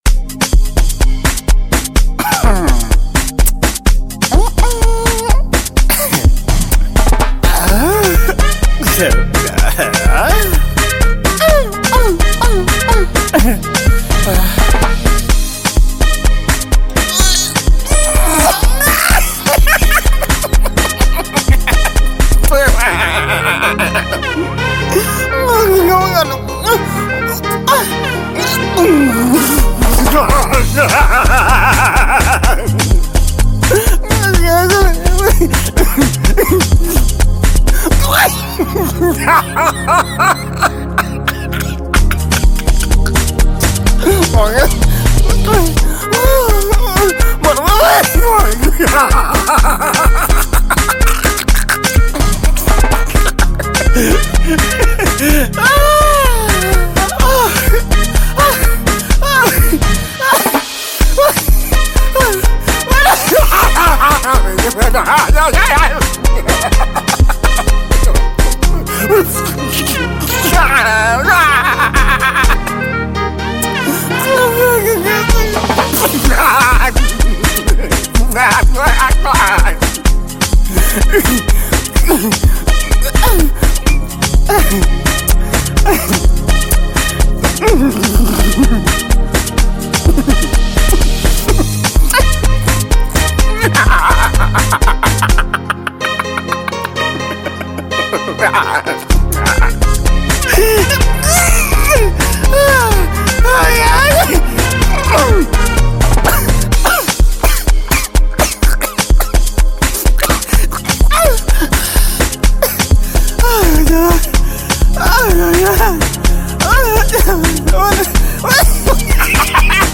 African Music
parody song